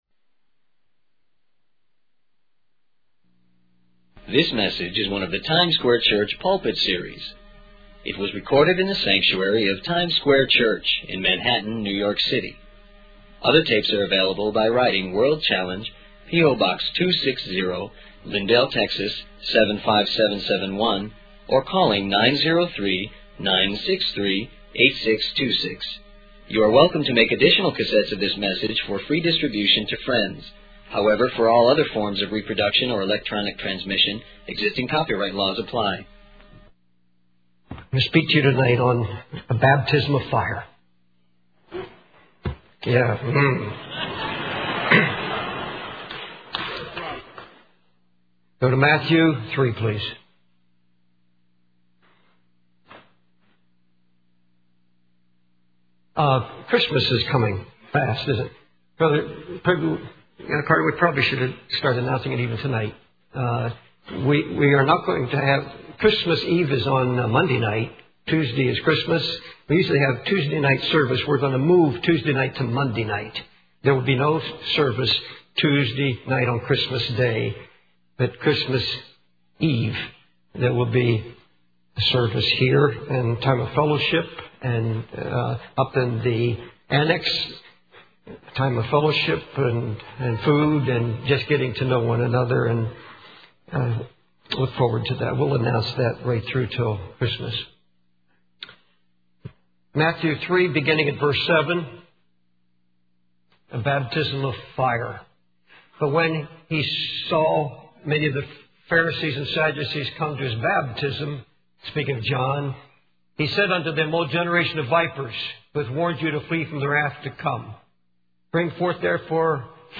In this sermon, the preacher describes a vision he had of people standing on the edge of a precipice, symbolizing their fear and despair. He emphasizes the importance of repentance and confession of sin, comparing it to the preaching of John the Baptist.